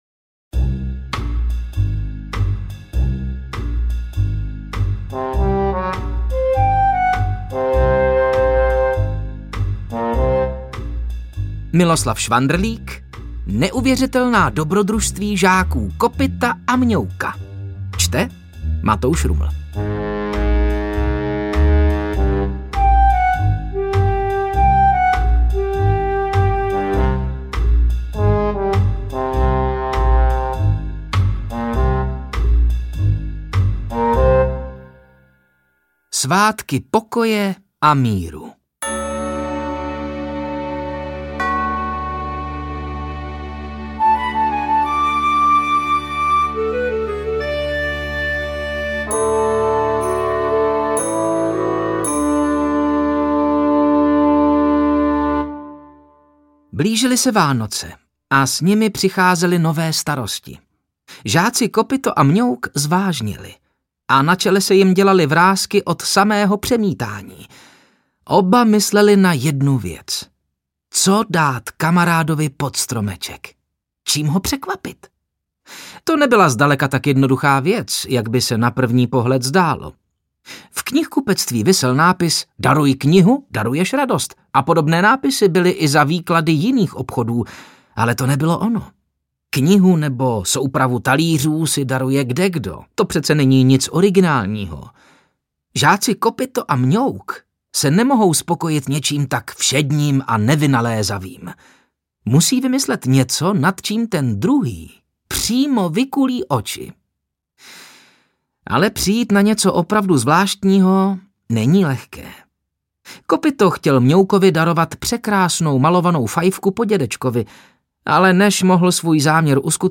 Interpret:  Matouš Ruml
Již podruhé oblíbený herec Matouš Ruml propůjčil svůj hlas žákům Kopytovi a Mňoukovi, panu učiteli Pěničkovi a řadě dalších postav ze slavné knižní série.